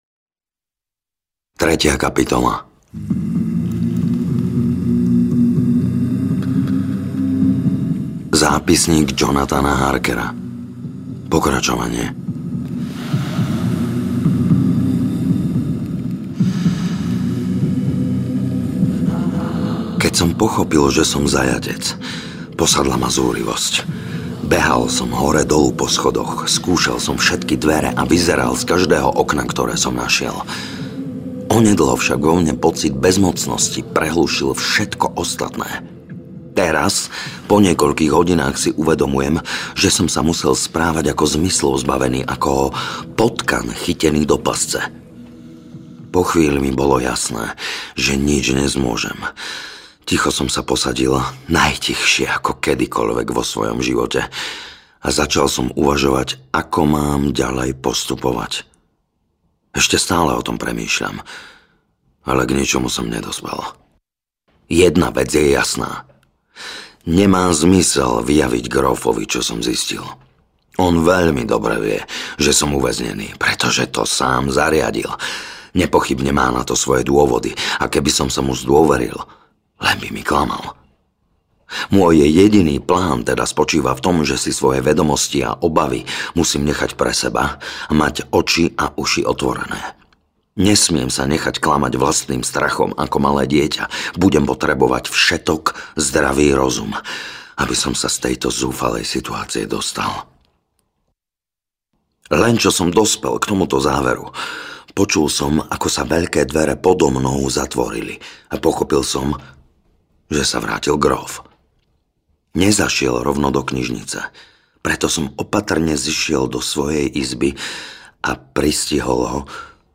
Dracula audiokniha